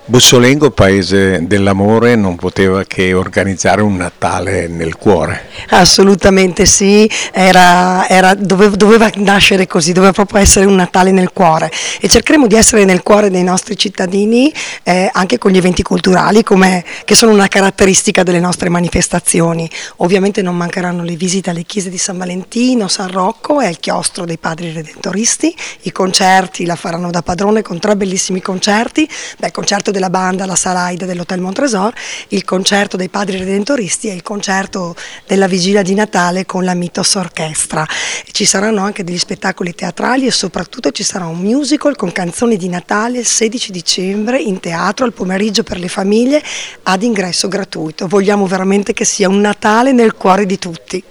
Valeria Iaquinta, assessore con delega alla cultura di Bussolengo